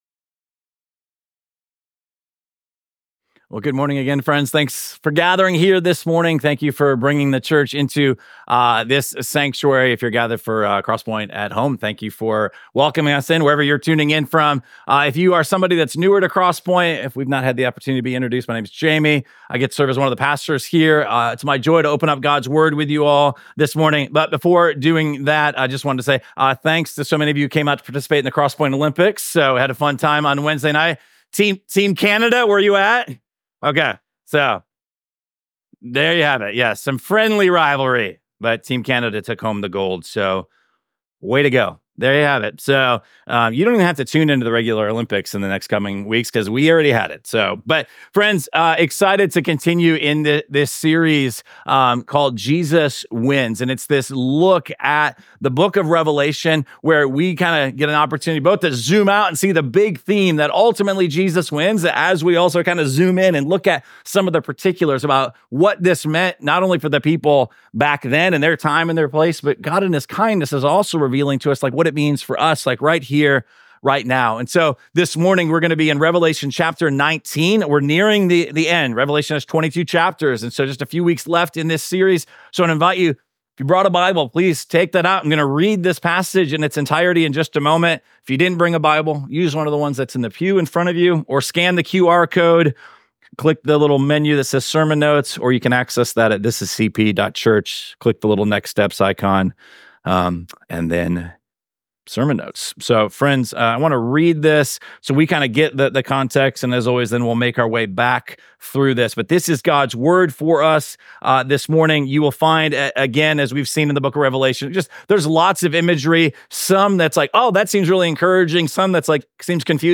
Week 14 of our series Jesus Wins: A Study of Revelation. This sermon comes from Revelation chapter 19.